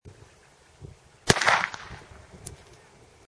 Descarga de Sonidos mp3 Gratis: petardo explosion.